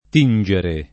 t&nJere] v.; tingo [